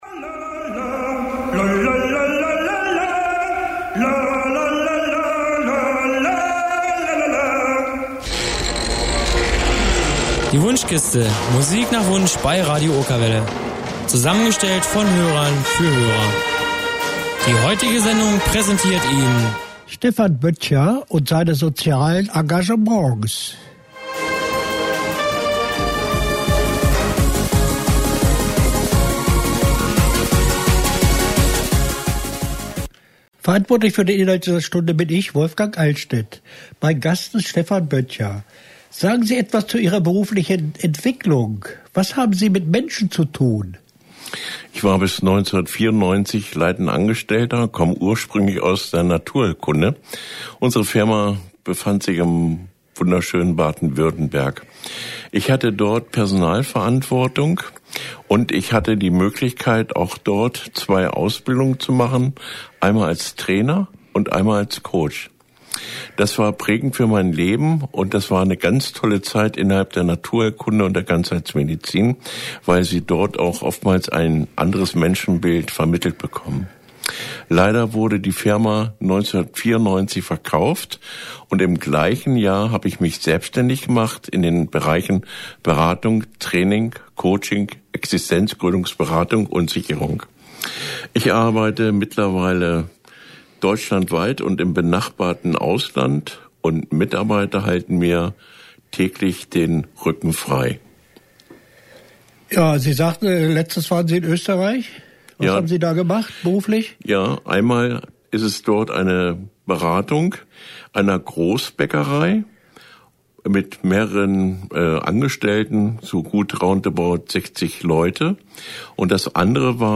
Interview bei Radio Okerwelle